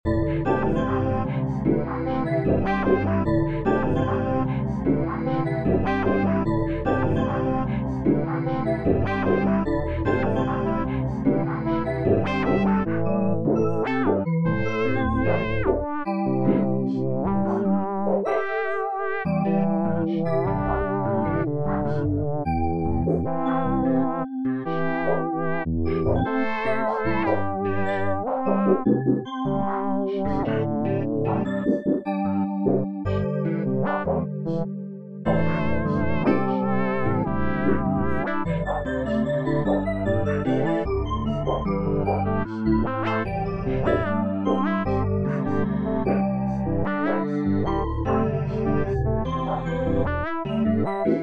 Home > Music > Beats > Bright > Ambient > Medium